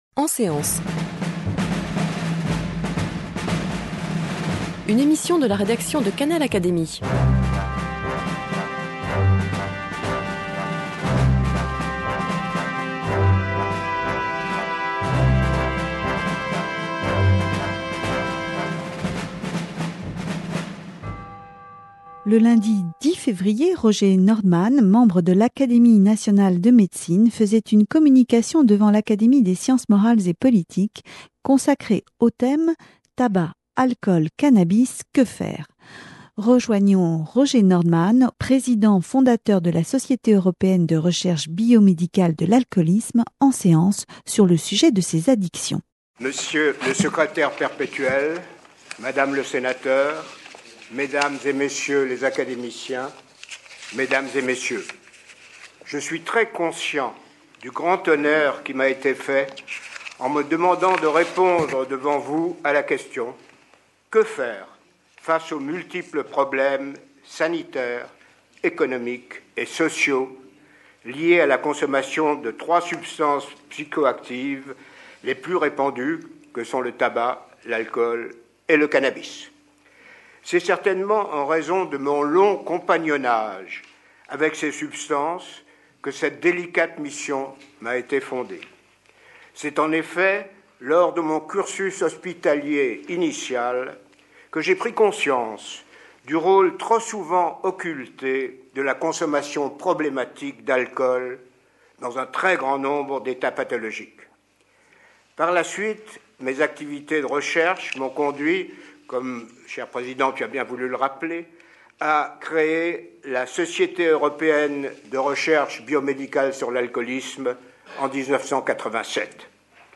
Voici la communication faite devant l’Académie des sciences morales et politiques le 12 février 2007.